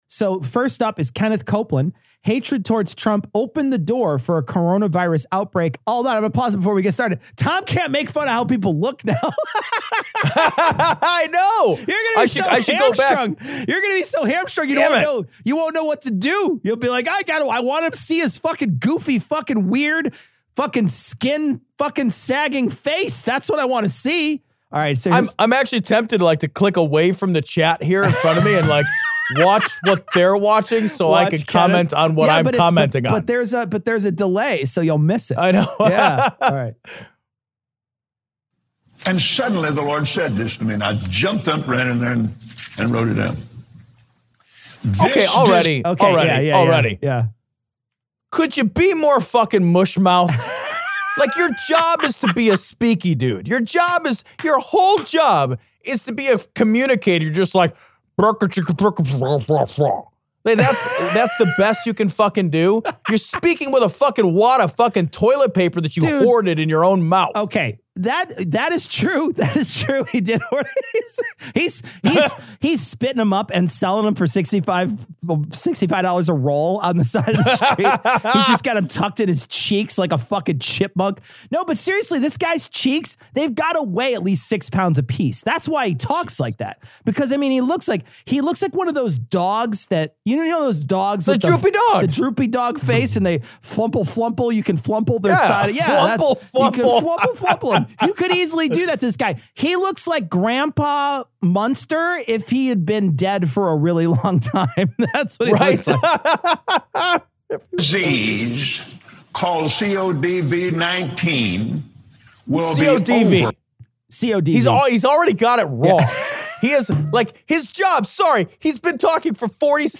LIVESTREAM AUDIO 2020-03-19" by Cognitive Dissonance Podcast from Patreon | Kemono